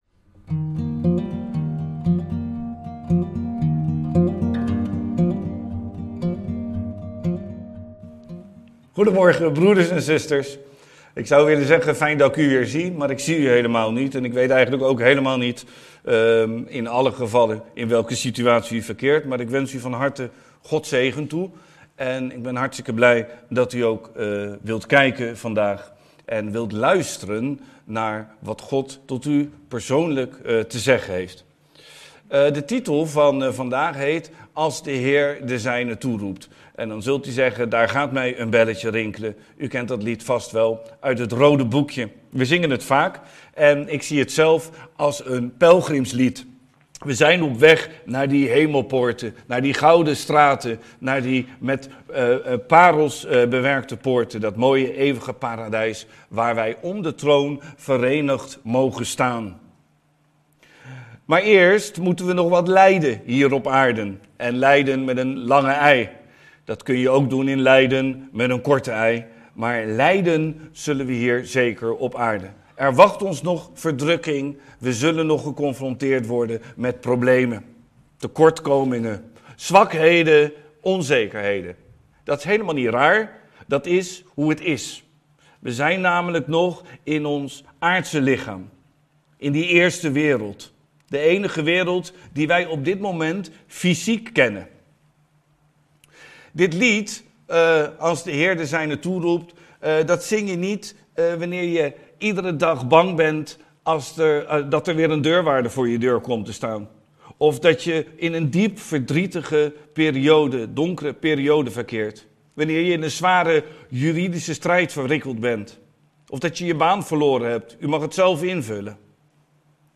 in Preken